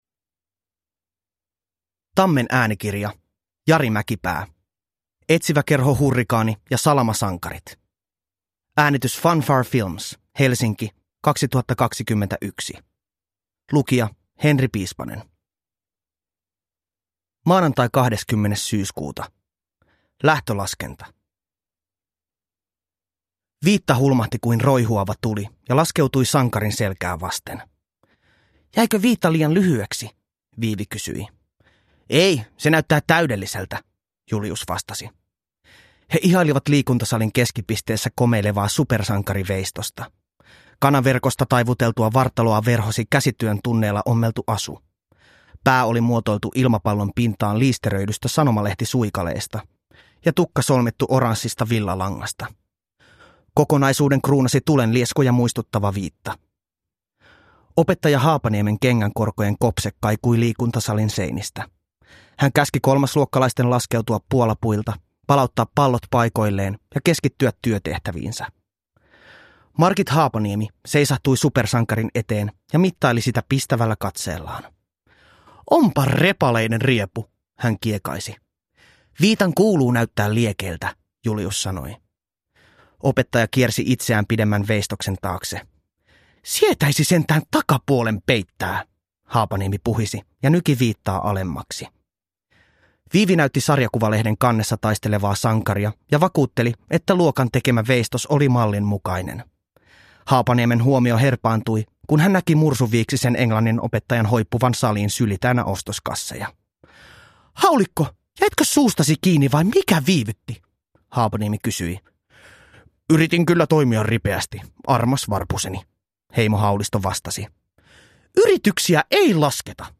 Etsiväkerho Hurrikaani ja Salamasankarit – Ljudbok – Laddas ner